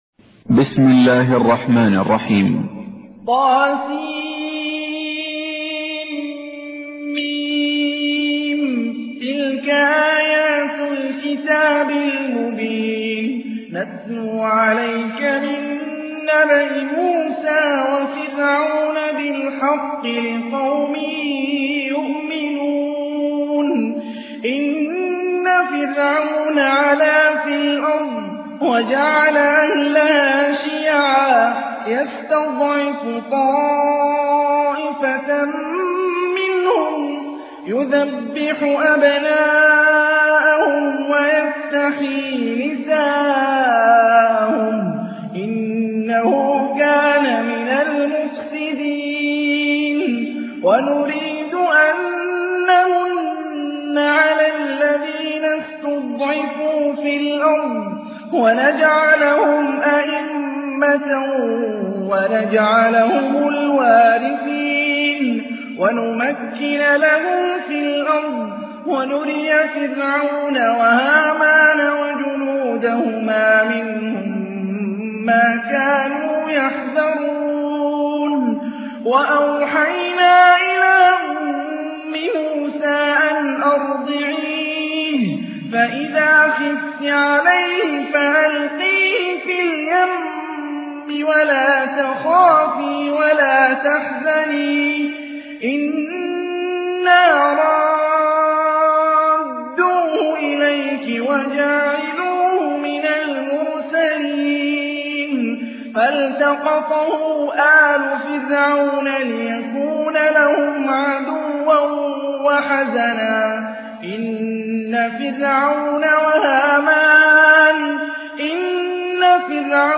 تحميل : 28. سورة القصص / القارئ هاني الرفاعي / القرآن الكريم / موقع يا حسين